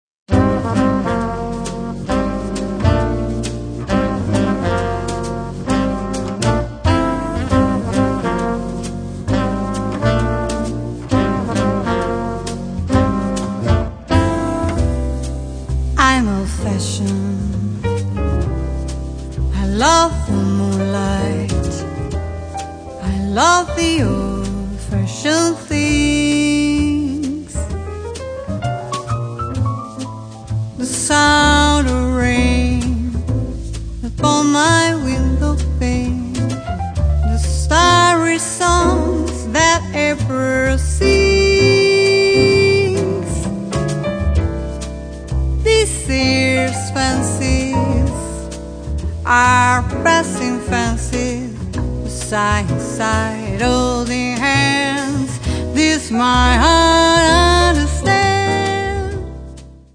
vocals
piano
alto saxophone
trumpet, flugelhorn
guitar
bass
drums